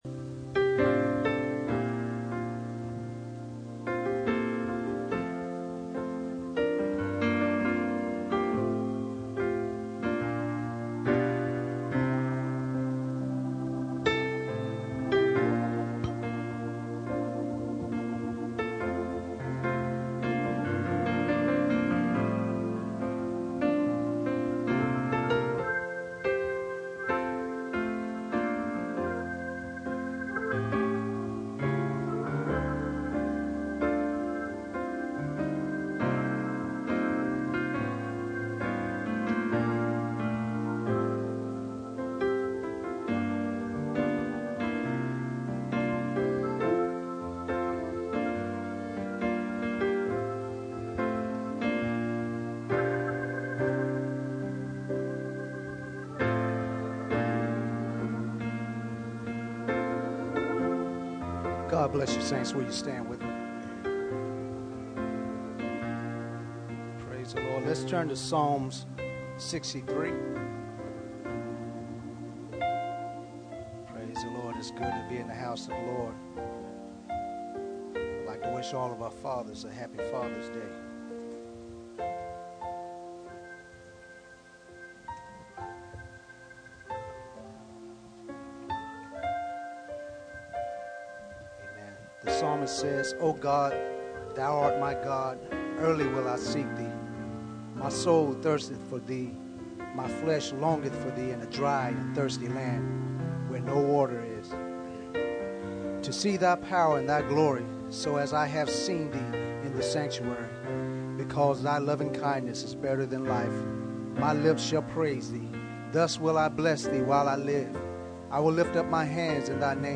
Guest Minister Service Type: Sunday Morning %todo_render% Church Ages 64